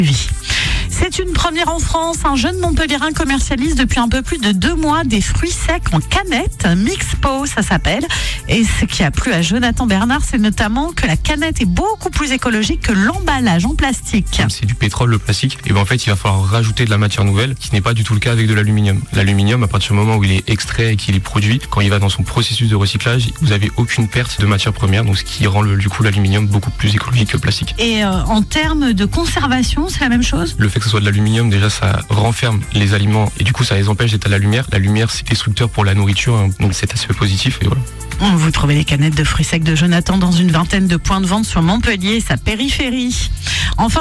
Écoutez nos passages radio :
Durant ces trois passages radio, diffusés sur Chérie FM, nous avons eu l’occasion de mettre en lumière :